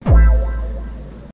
Love Impact Téléchargement d'Effet Sonore